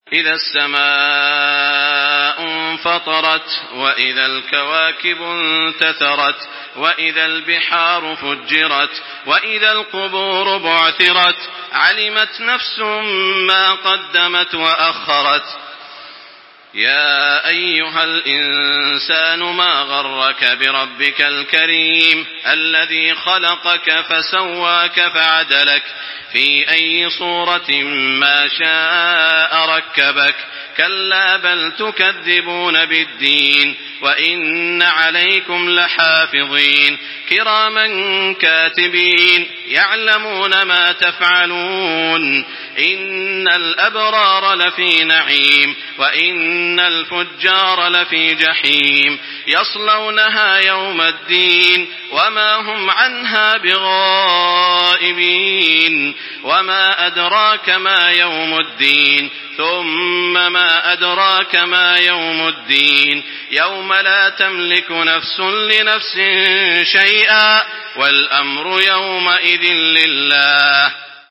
Surah Al-Infitar MP3 in the Voice of Makkah Taraweeh 1425 in Hafs Narration
Listen and download the full recitation in MP3 format via direct and fast links in multiple qualities to your mobile phone.
Murattal